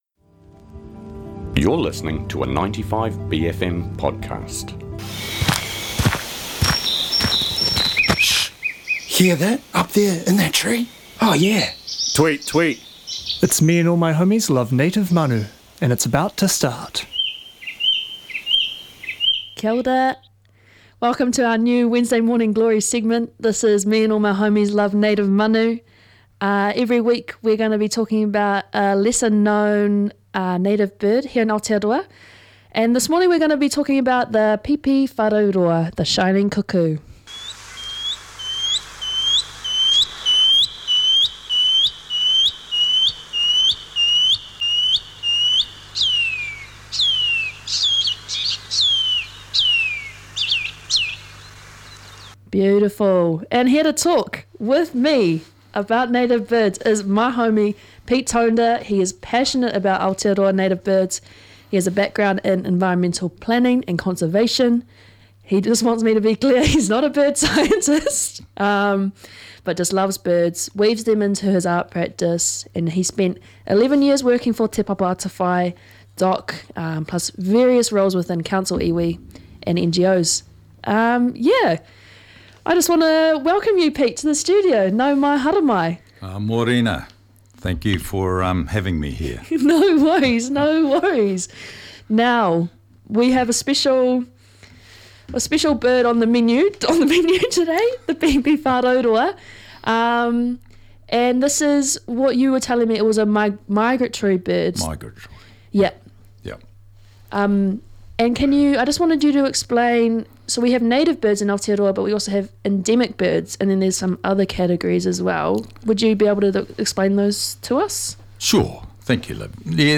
the final manu chat of the year and this week we are having a kōrero about te kotare/sacred kingfisher! a special native bird which needs no introduction :) whakarongo mai nei!